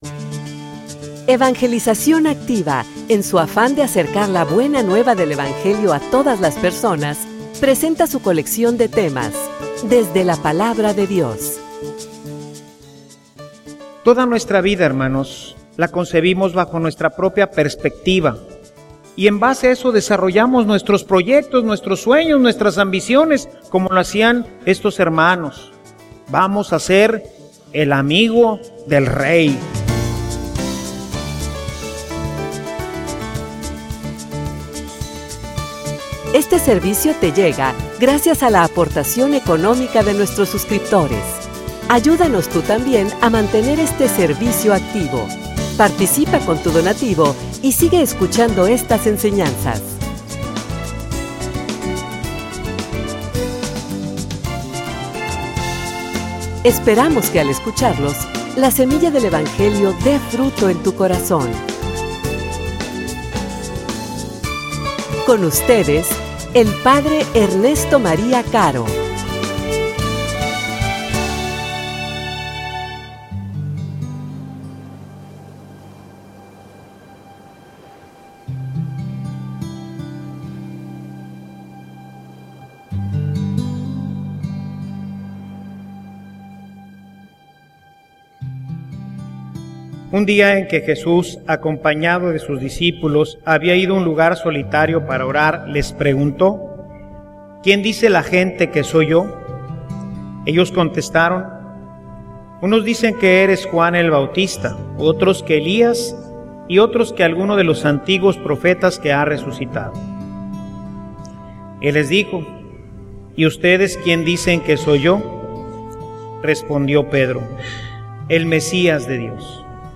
homilia_Quien_dice_Dios_que_soy_yo.mp3